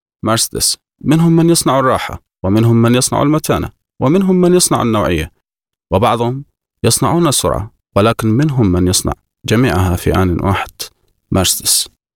Yabancı Seslendirme Kadrosu, yabancı sesler